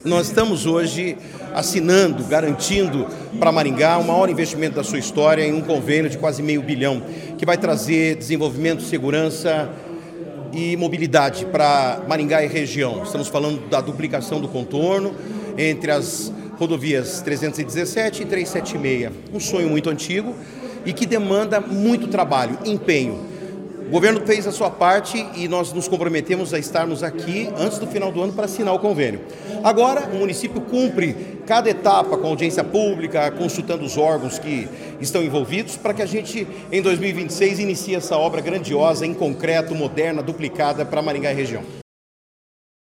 Sonora do secretário de Infraestrutura e Logística, Sandro Alex, sobre a formalização do convênio para a nova etapa da duplicação do Contorno Sul de Maringá